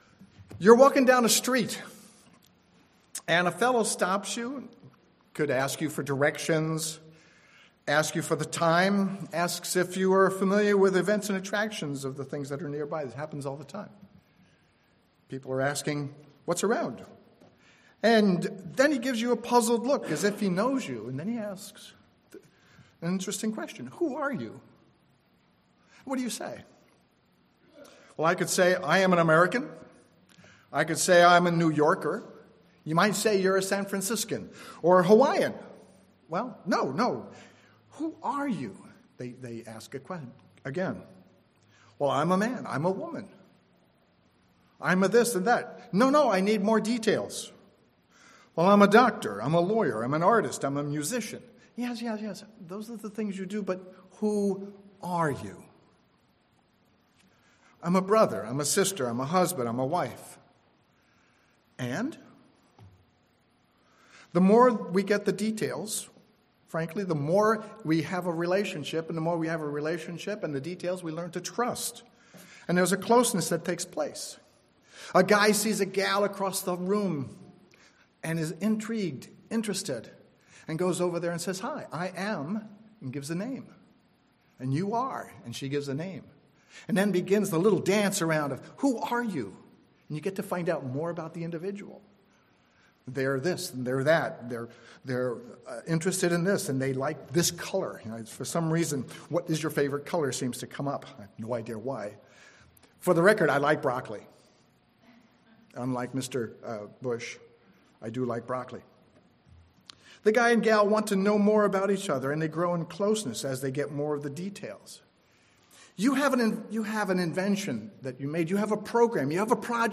This sermon examines how Christ amplified that answer in the New Testament.